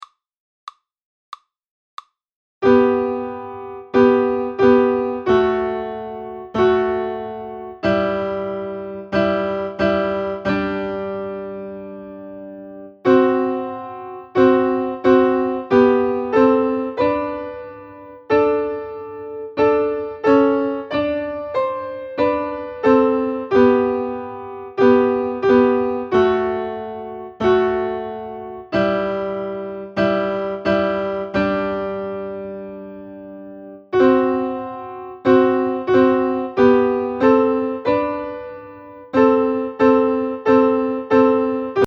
hymnaudio2019sansvoix1.mp3